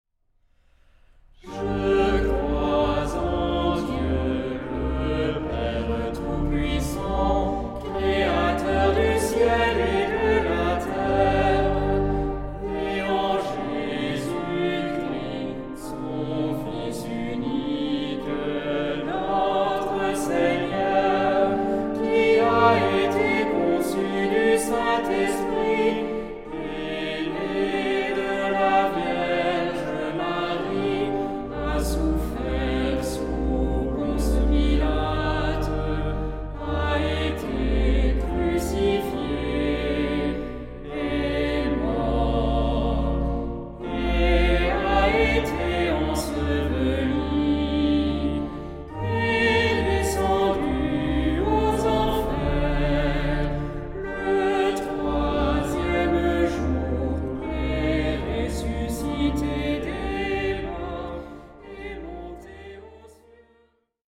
Tonalité : sol mineur